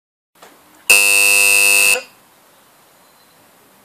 Hard Wired Doorbell F105 Kit- Electric Hard Wired Doorbell for Office Shop
office-doorbell-f105.mp3